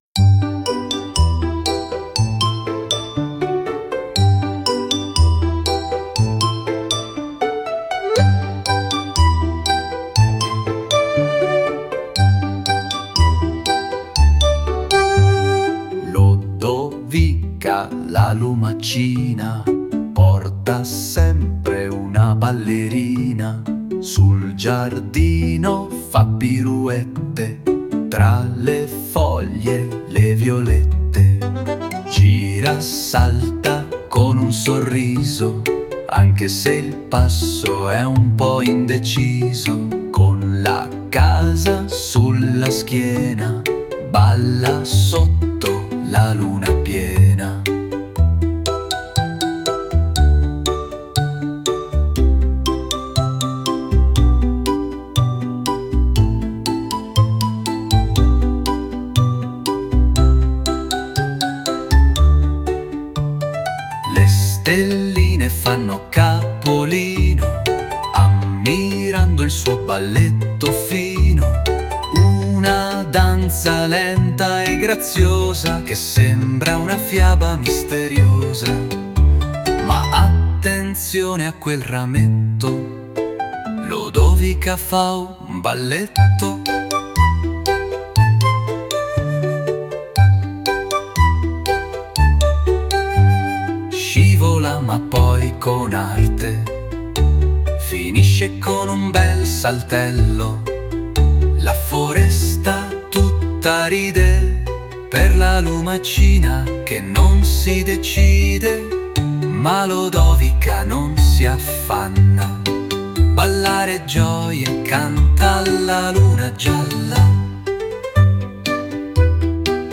la filastrocca di Lodovica la lumachina ballerina che scopre che può ballare… anche se lentamente!